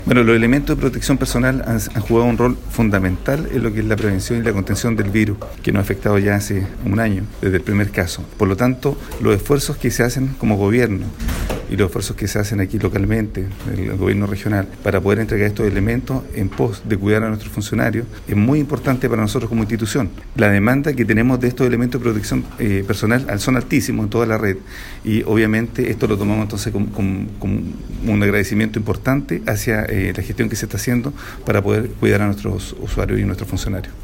Cuña-René-Lopetegui-director-del-Servicio-de-Salud-Araucanía-Sur.mp3